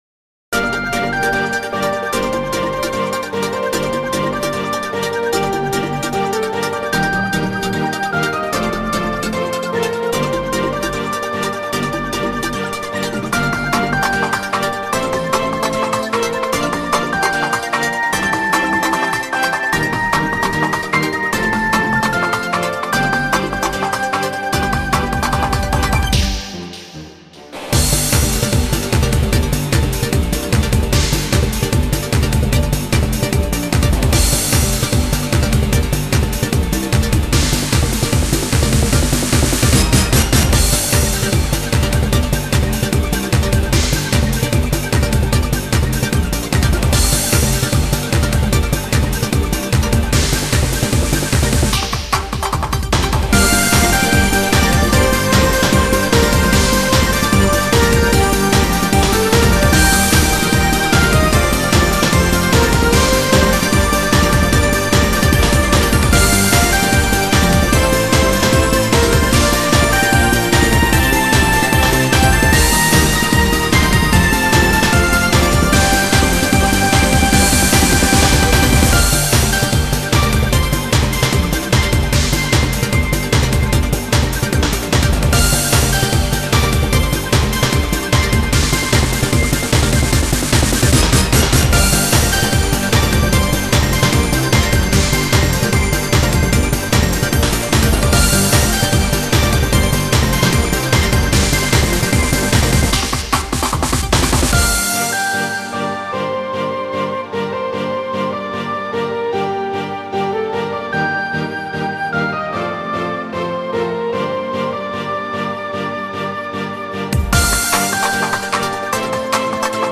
[2009.1.1UP/128kbps/3.17MB/Genre：Techno Pop/Instrumental]